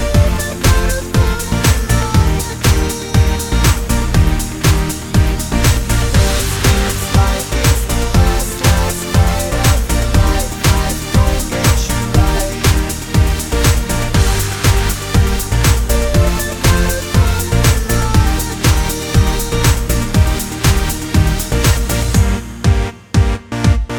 no Backing Vocals R'n'B / Hip Hop 3:42 Buy £1.50